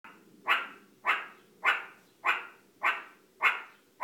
Acoustic cues to identity and predator context in meerkat barks
Meerkats, Suricata suricatta, produce broadband noisy bark vocalizations, lacking a clear fundamental frequency and harmonic structure, when they detect aerial or terrestrial predators.
Acoustic analyses of naturally occurring barks and measurements of this species' vocal tract length were used to confirm that the six clear frequency bands below 15 kHz in meerkat barks represent formants.
Bark, Formant, Functional reference, Individuality, Meerkat (Suricata suricatta)